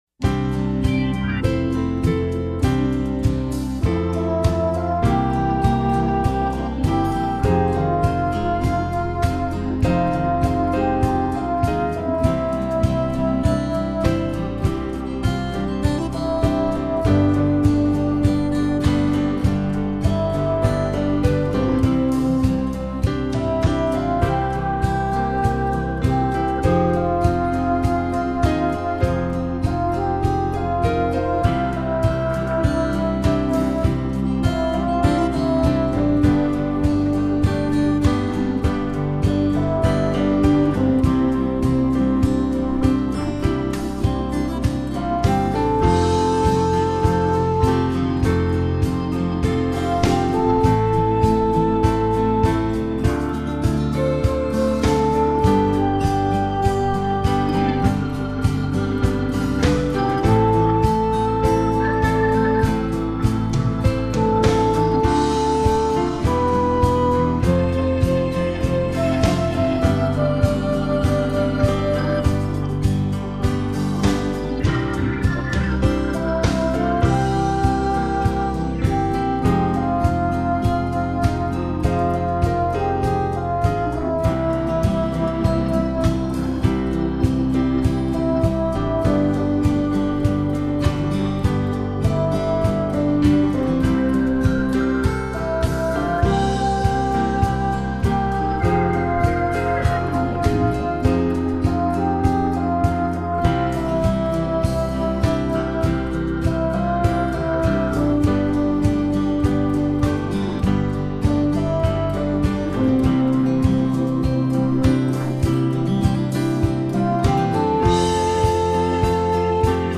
Vision of the Gifts | Original Catholic Liturgical Music in the Creative Commons
It really goes a bit high but perhaps a soloist could give it a go.